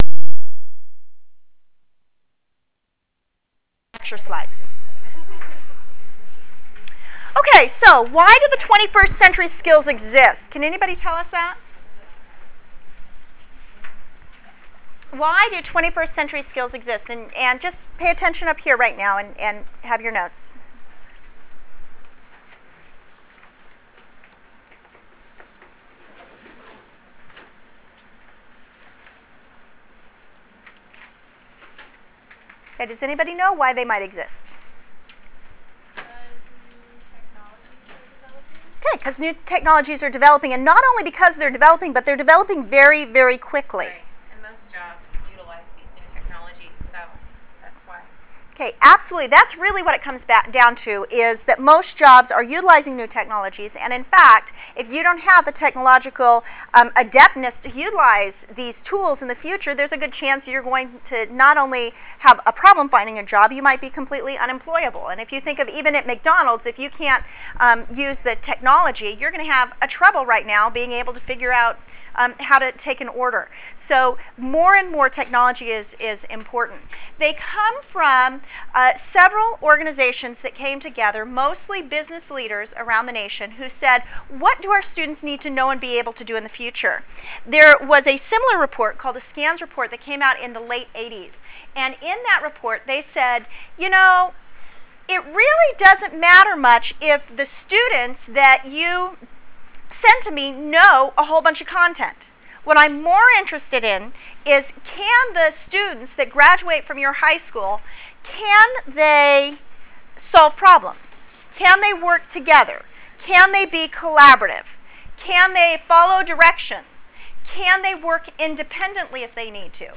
Lectures_Curriculum_Instruction_Assessment_9_11_06.wav